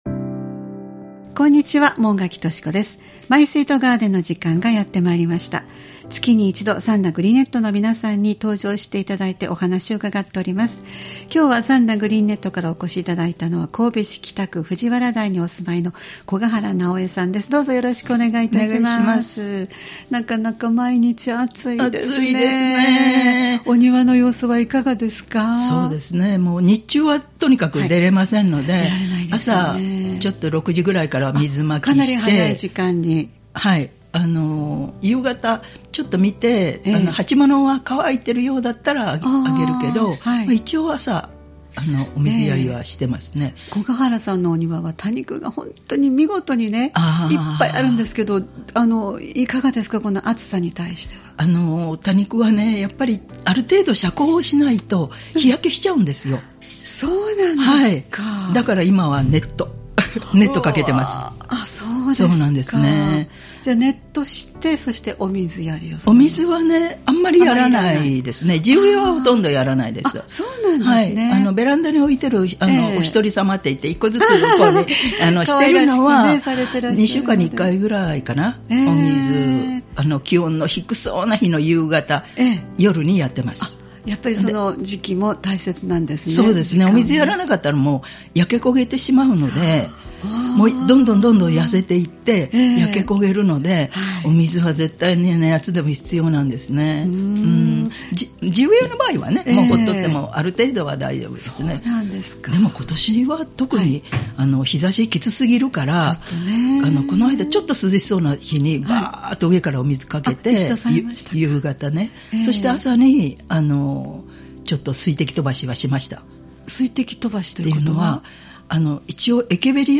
毎月第1火曜日は兵庫県三田市、神戸市北区、西宮市北部でオープンガーデンを開催されている三田グリーンネットの会員の方をスタジオにお迎えしてお庭の様子をお聞きする「マイスイートガーデン」をポッドキャスト配信しています（再生ボタン▶を押すと番組が始まります）